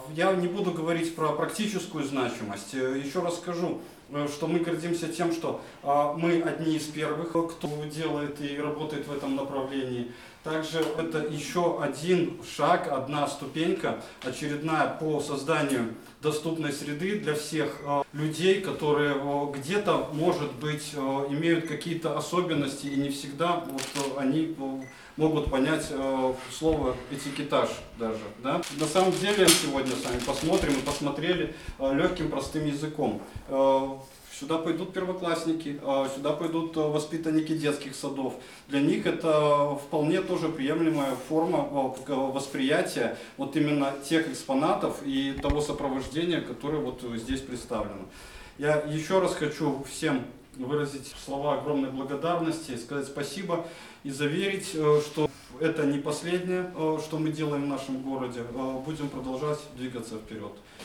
Руководство города идёт навстречу таким инициативам, оказывает и будет оказывать поддержку людям с ограниченными возможностями, а данный проект еще один инструмент для создания безбарьерной среды, отметил зампредседателя горисполкома Вадим Щербаков